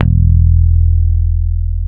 -JP PICK E.2.wav